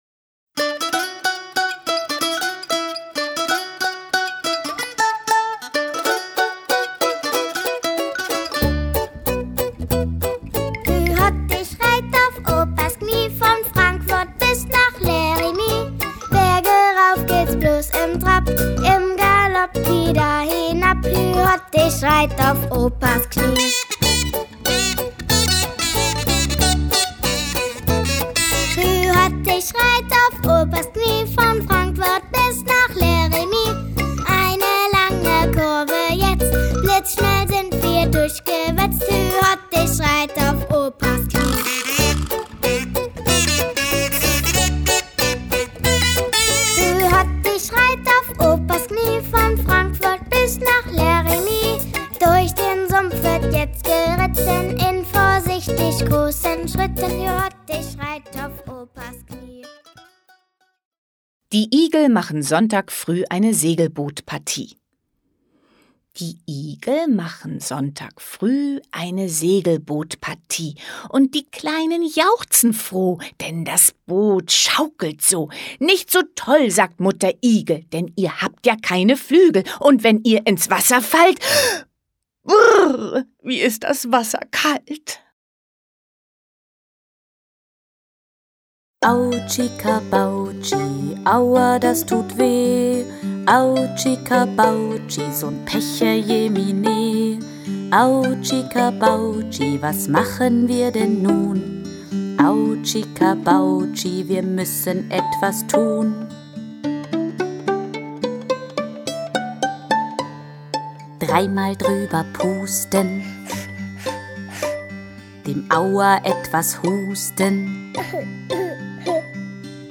Einfach Fingerspiele, fröhliche Kinderreime und sowohl traditionelle als auch neue Kinderlieder schulen die Motorik und fördern das Sprachgefühl der Allerkleinsten.
Schlagworte Bewegungsförderung • Fährt ein Schifflein • Fingerspiele • Heile, heile, Segen • Hoppe, hoppe Reiter • Liebe Sonne, komm heraus • Sprachförderung • Sprachgefühl • traditionelle Kinderlieder